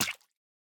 Minecraft Version Minecraft Version snapshot Latest Release | Latest Snapshot snapshot / assets / minecraft / sounds / mob / tadpole / death1.ogg Compare With Compare With Latest Release | Latest Snapshot